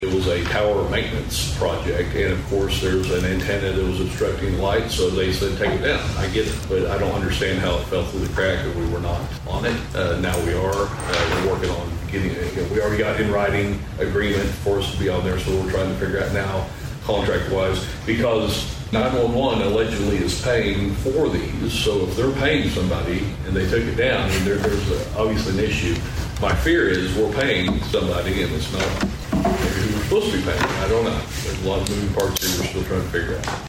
During the citizen's input portion of Tuesday's Osage County Commissioners meeting, Sheriff Bart Perrier informed the Board of some radio connectivity issues they had faced over the weekend.